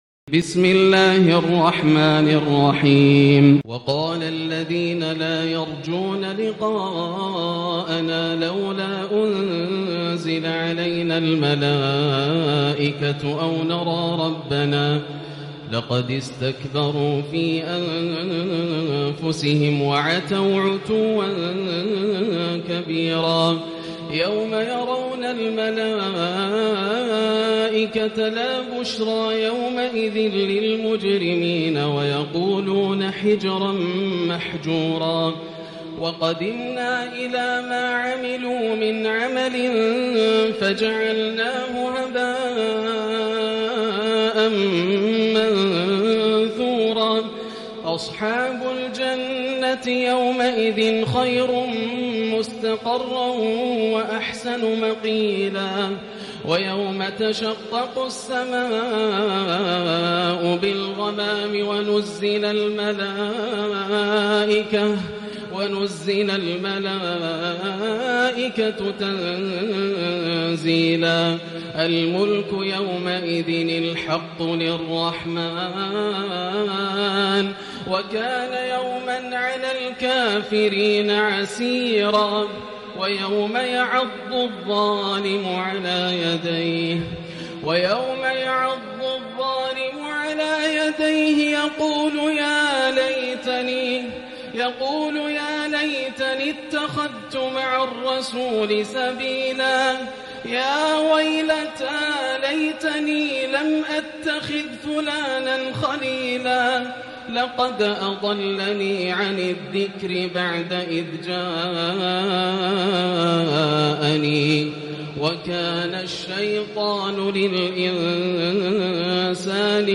الجزء التاسع عشر > مصحف الشيخ ياسر الدوسري (مصحف الأجزاء) > المصحف - تلاوات ياسر الدوسري